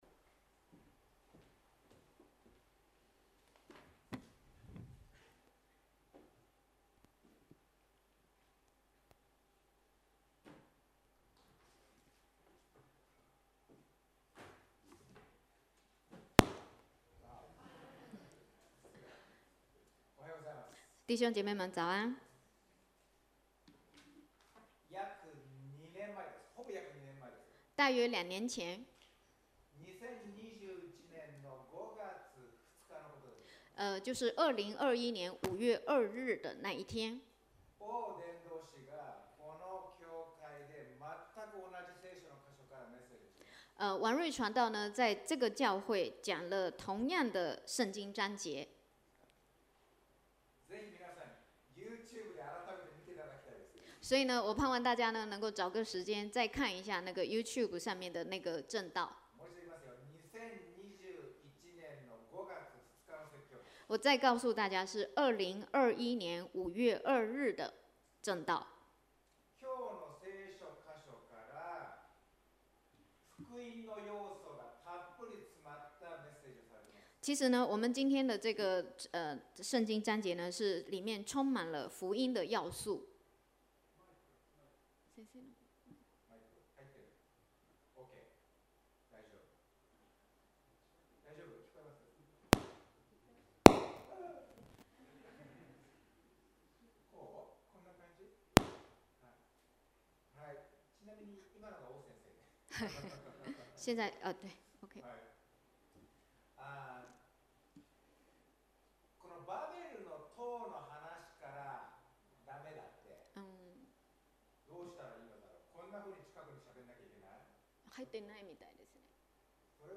今天想要通過今年的教會主題「彼此相愛，主裡相交」這個角度從兩個方面跟大家一起分享。